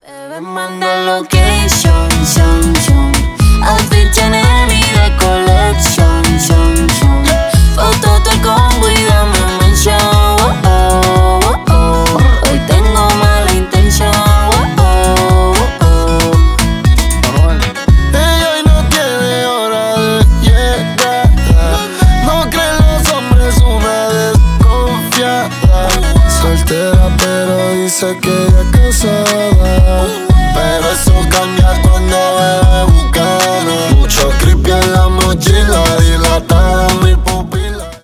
Поп Музыка
латинские # клубные